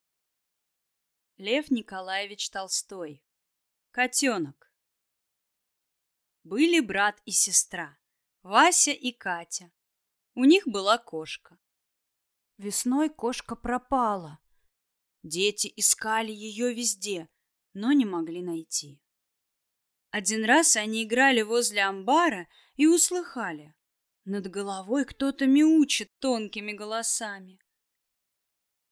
Аудиокнига Котёнок | Библиотека аудиокниг